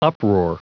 Prononciation du mot uproar en anglais (fichier audio)
Prononciation du mot : uproar
uproar.wav